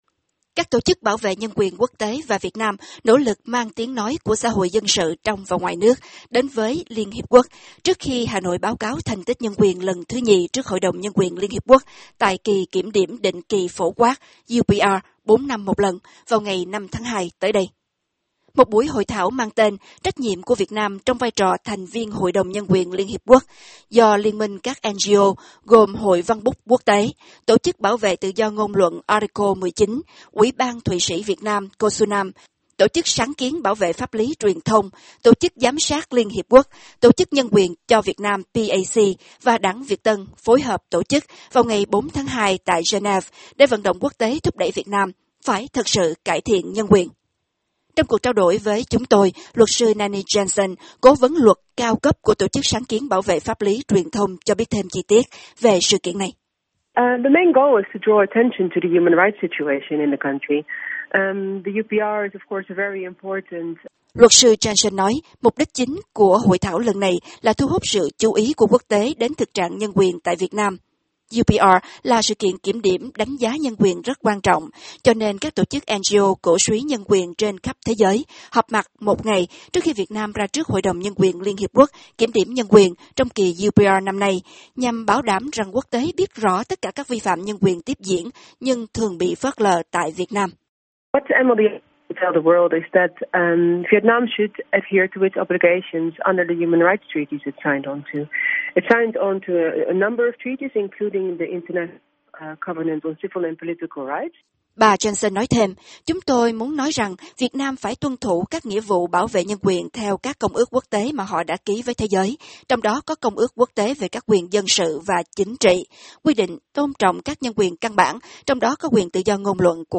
by VOA Tiếng Việt